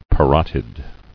[pa·rot·id]